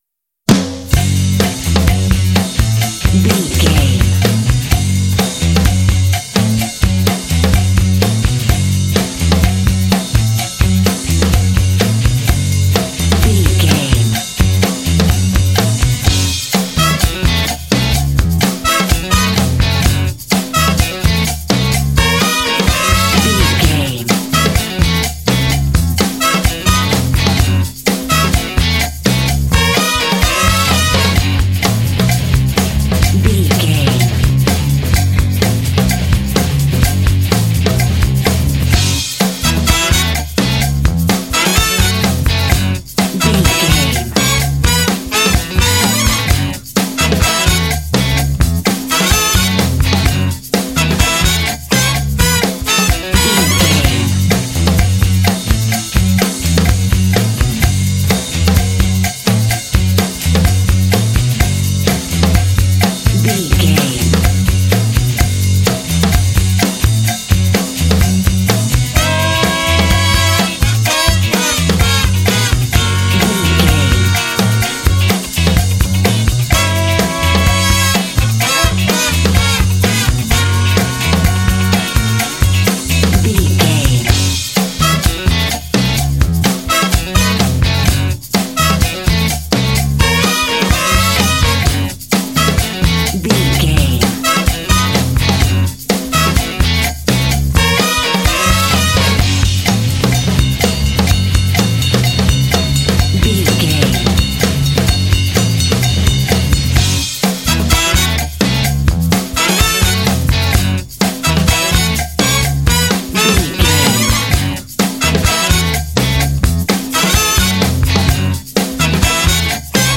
Uplifting
Aeolian/Minor
F#
driving
powerful
energetic
groovy
horns
brass
drums
electric guitar
bass guitar